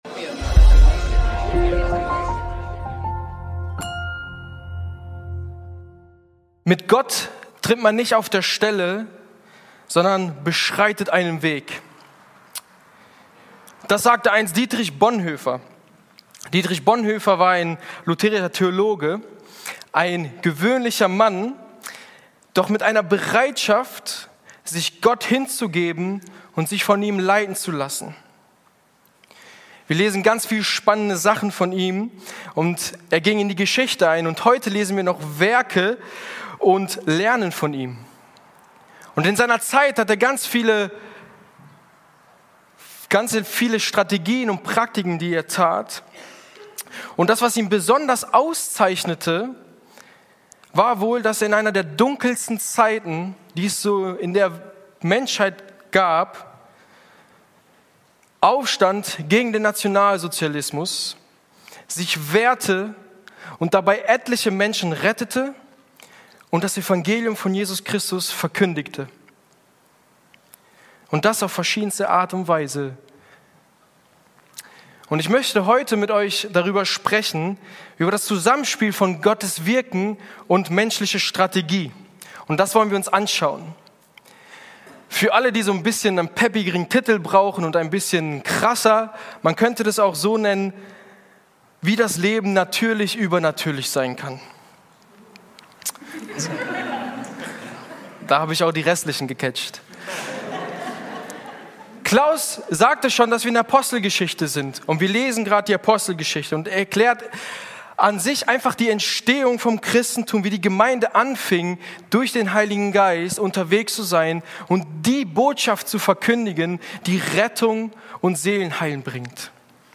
Gottes Mission - Menschliche Strategie 1 ~ Predigten der LUKAS GEMEINDE Podcast